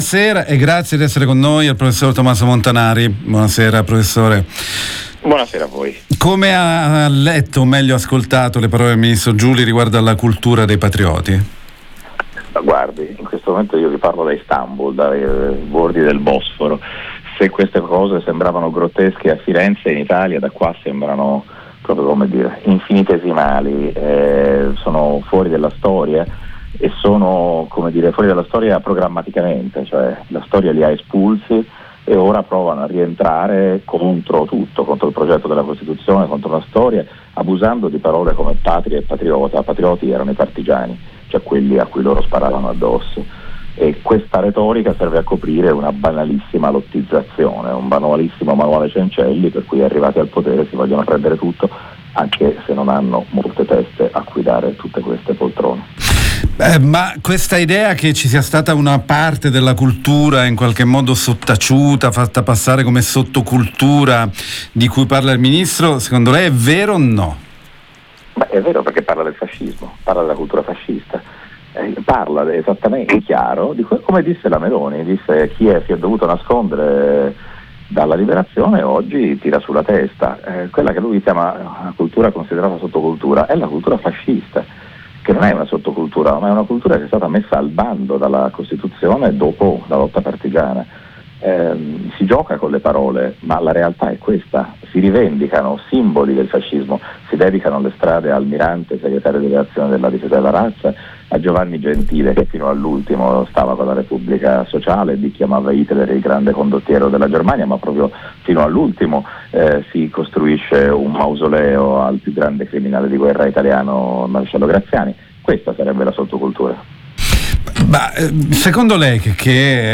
MontanariAbbiamo chiesto un parere al professor Tomaso Montanari sulle parole del ministro Giuli circa il fatto che il nuovo governo stia dando dignità una cultura che era ritenuta ‘sottocultura antisistema’.